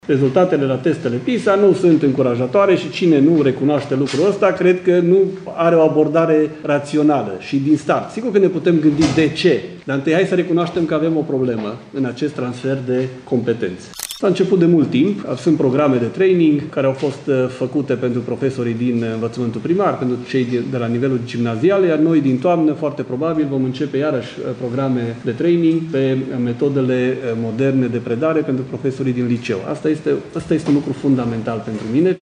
Ministrul Educației, Daniel David: Întâi hai să recunoaștem că avem o problemă în acest transfer de competențe